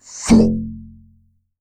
TSK  1M.wav